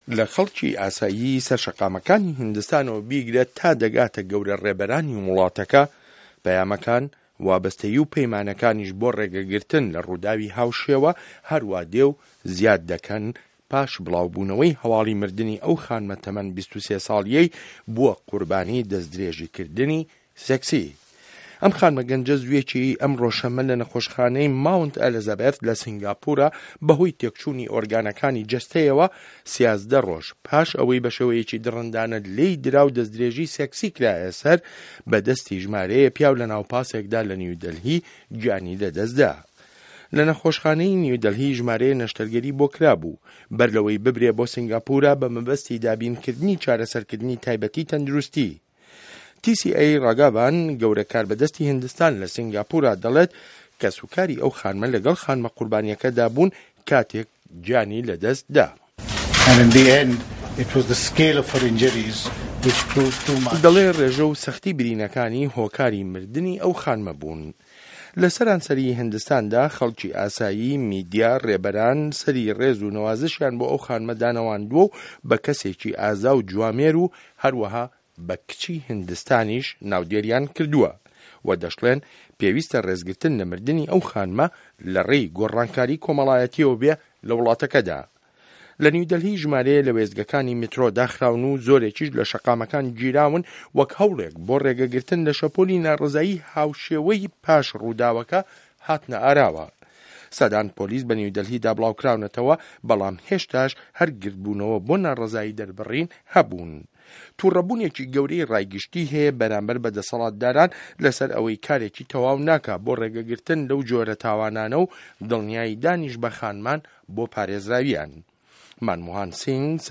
ڕاپۆرت له‌سه‌ر ده‌سدتدرێژی کردنی سێکسی بۆسه‌ر کچێکی خوێندکار